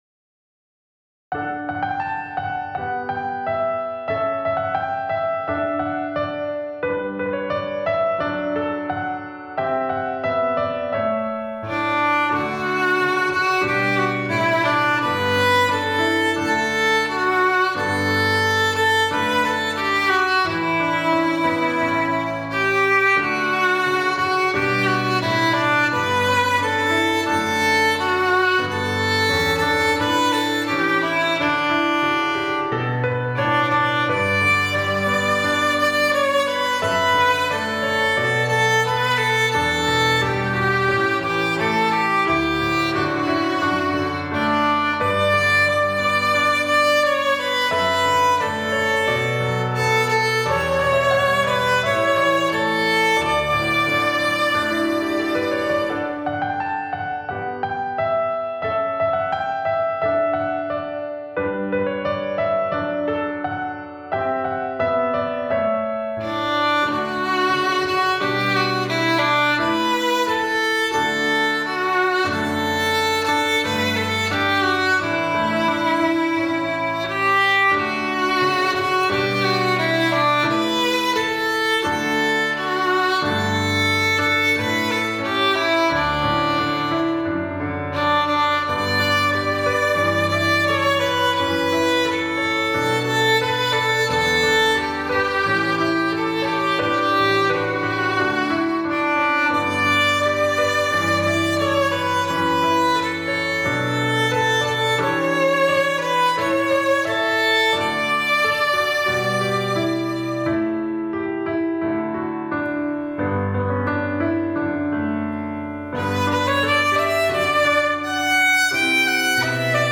Arrangement for beginning string players of the hymn
Instrumentation: Violin, Viola, Cello, Bass, Piano